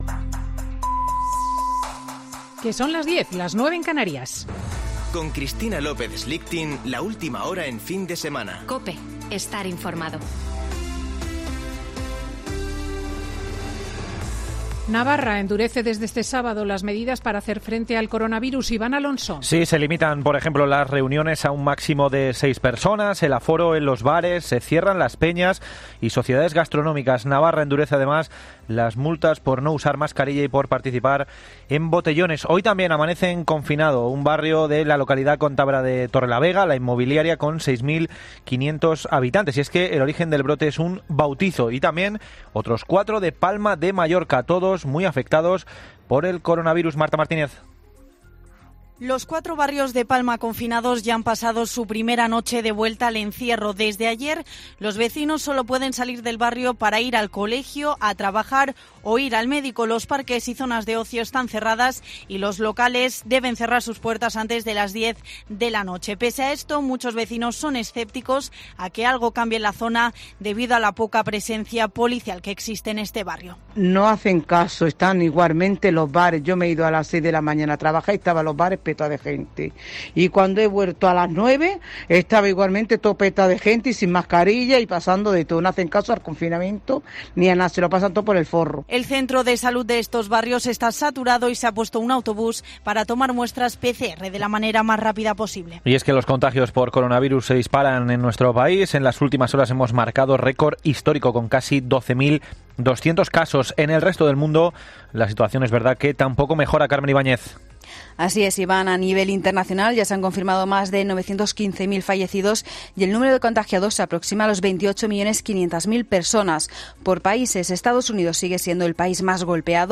Boletín de noticias de COPE del 12 de septiembre de 2020 a las 10.00 horas